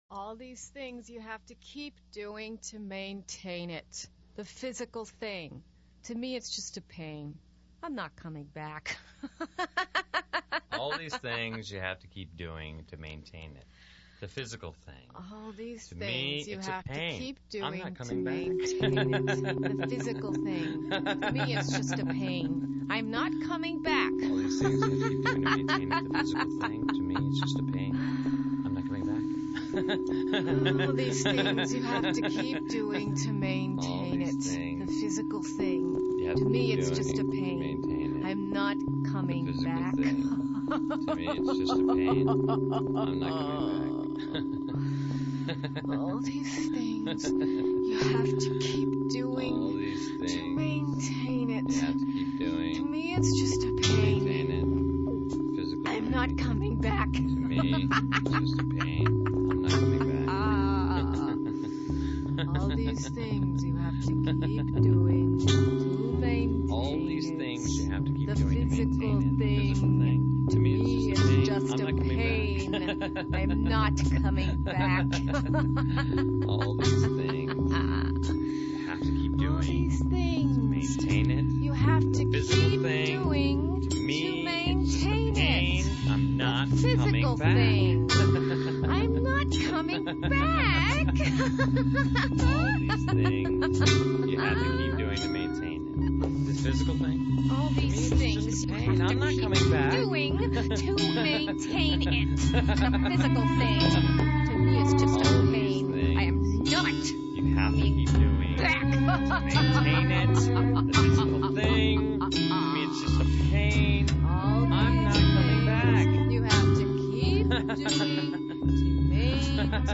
gamelan revival